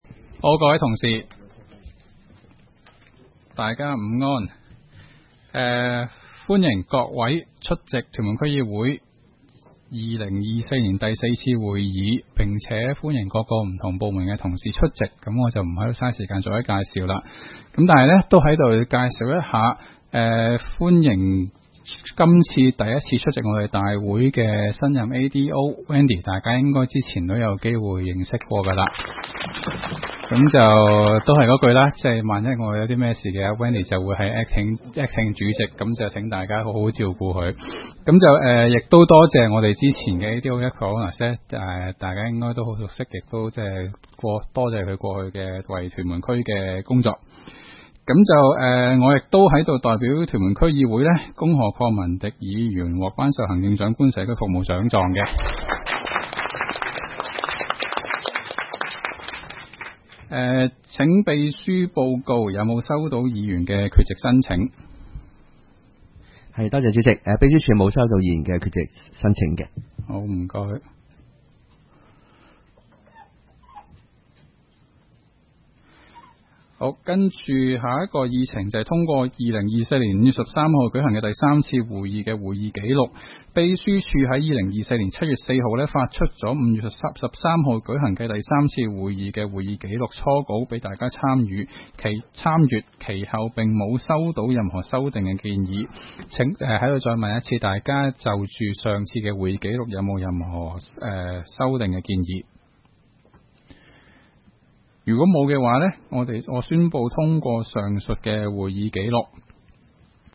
區議會大會的錄音記錄
屯門區議會會議室